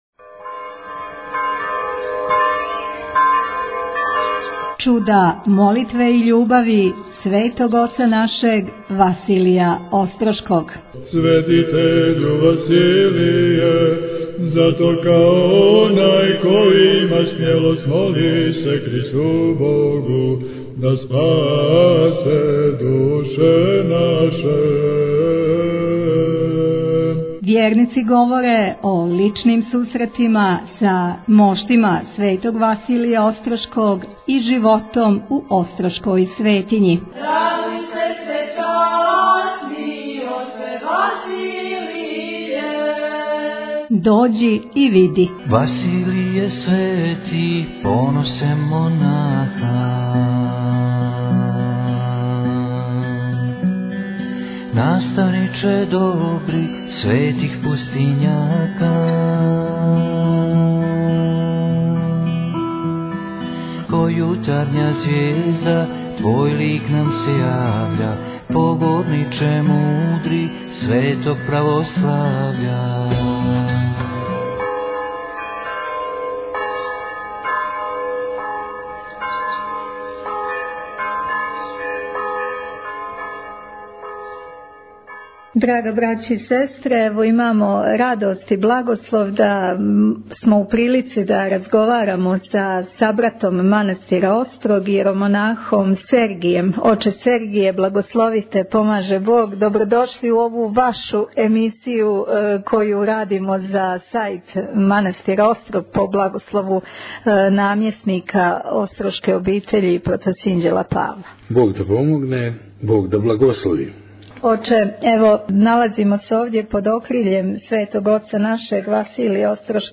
Овог љета имали смо благослов да у манастиру Острог, поред моштију Светог Василија Острошког Чудотворца, снимимо више интересантних разговора са нашим свештеницима и поклоницима Острошке Светиње.